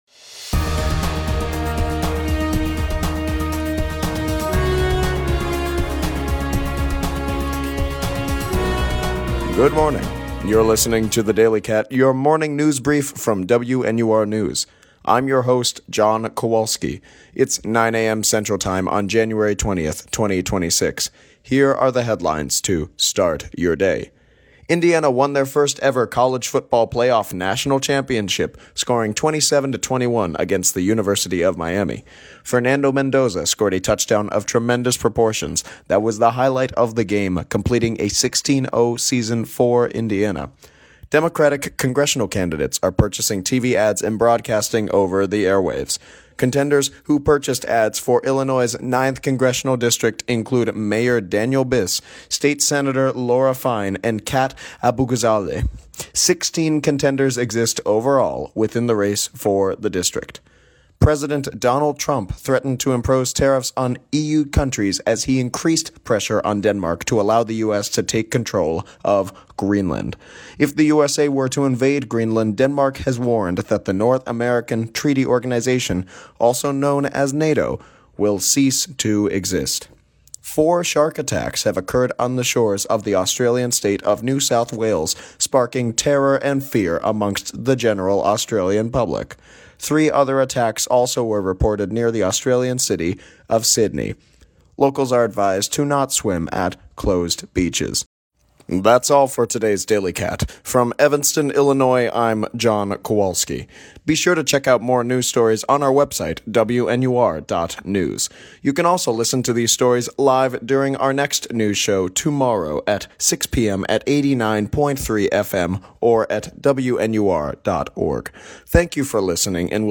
WNUR News’ Daily Briefing – January 20th, 2026